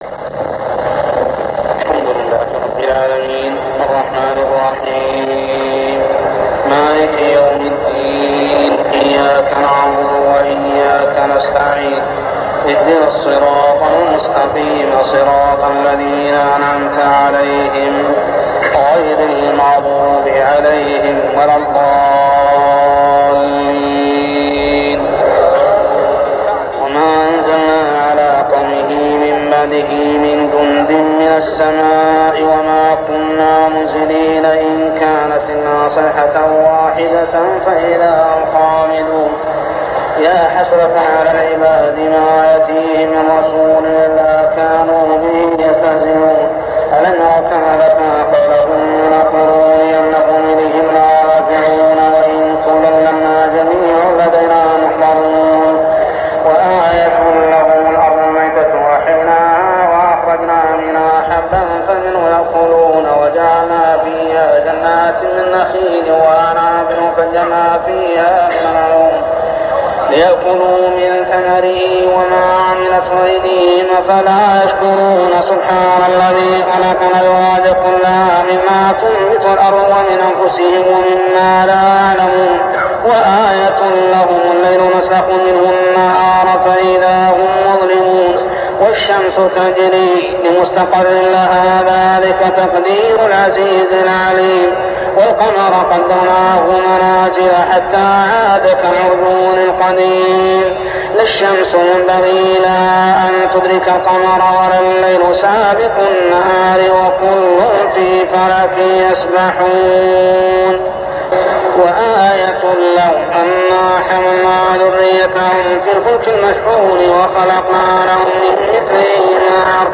صلاة التراويح عام 1399هـ سورتي يسٓ 28-83 و الصافات 1-148 | Tarawih prayer Surah Yasin and As-Saffat > تراويح الحرم المكي عام 1399 🕋 > التراويح - تلاوات الحرمين